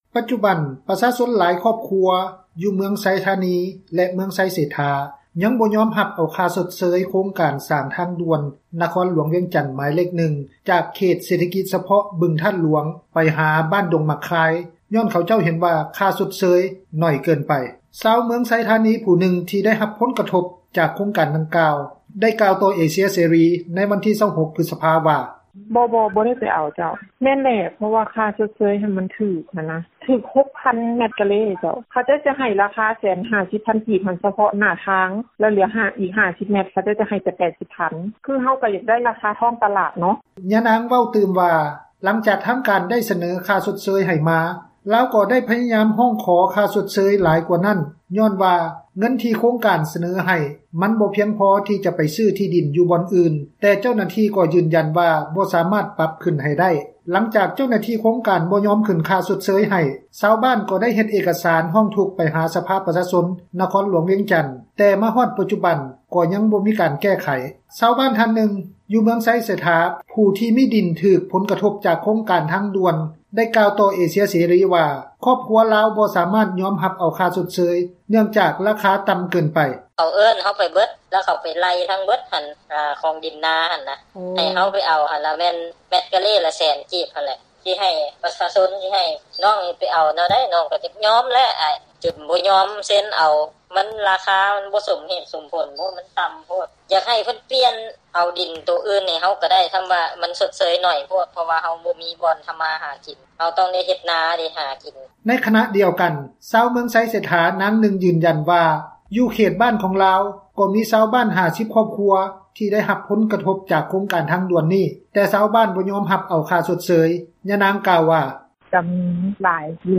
ຊາວບ້ານທ່ານນຶ່ງ ຢູ່ເມືອງໄຊເສດຖາ ທີ່ຖືກຜົນກະທົບຈາກໂຄງການທາງດ່ວນ ໝາຍເລກ-1 ໄດ້ກ່າວຕໍ່ເອເຊັຽເສຣີ ວ່າຄອບຄົວຂອງລາວ ບໍ່ສາມາດຍອມຮັບເອົາ ຄ່າຊົດເຊີຍນັ້ນໄດ້ ເນື່ອງຈາກວ່າລາຄາຕ່ຳ ເກີນໄປ:
ຊາວບ້ານທີ່ໄດ້ຮັບຜົນກະທົບ ບໍ່ໄດ້ເຂົ້າມາສະເໜີ ຜ່ານອຳນາດການປົກຄອງບ້ານ, ເຈົ້າໜ້າທີ່ຂັ້ນທ້ອງຖິ່ນ ທ່ານນຶ່ງໄດ້ກ່າວວ່າ: